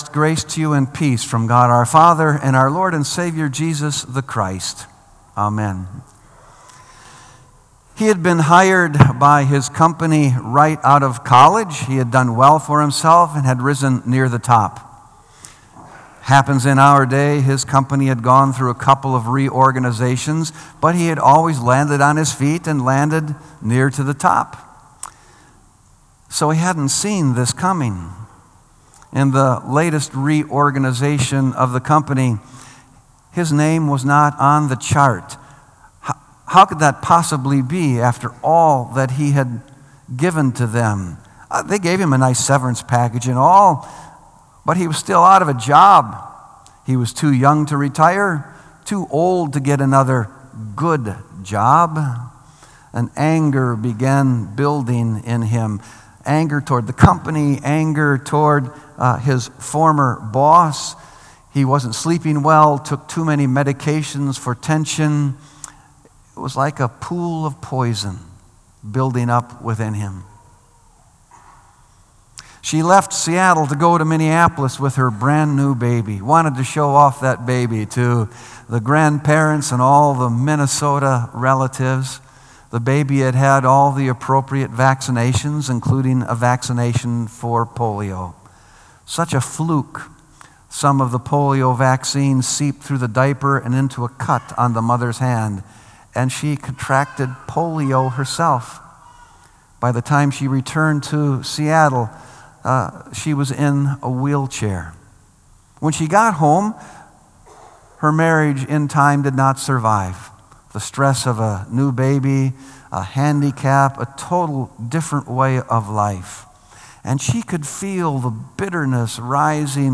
Sermon ” Pockets of Poison – Unforgiving Hearts”